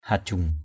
/ha-ʥuŋ/